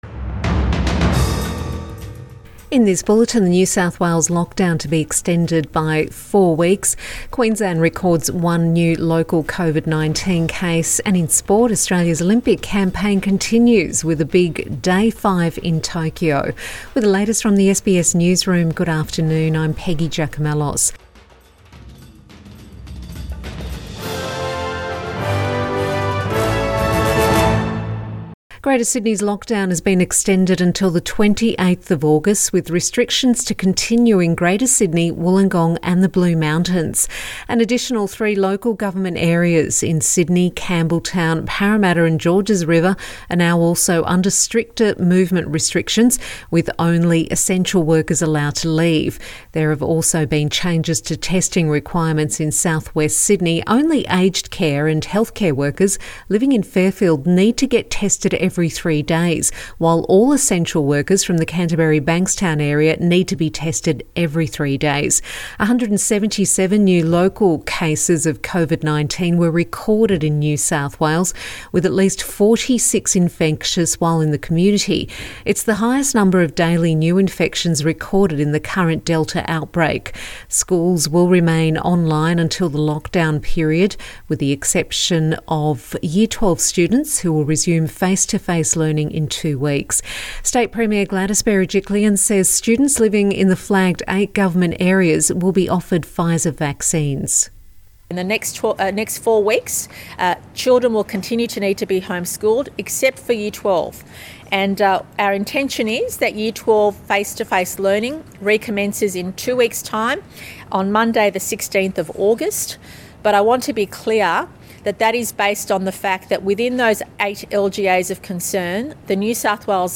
Midday bulletin 28 July 2021